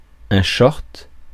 Ääntäminen
Synonyymit entier court Ääntäminen France: IPA: [œ̃ ʃɔʁt] Tuntematon aksentti: IPA: /ʃɔʁt/ Haettu sana löytyi näillä lähdekielillä: ranska Käännös 1. šortsid Suku: m .